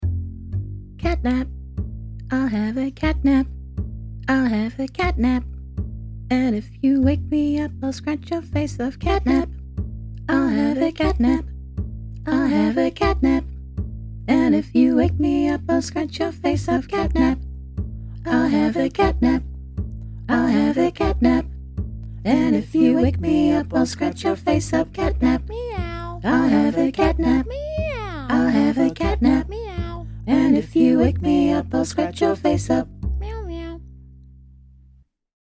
Also I love the bass - and all the space around the vocal.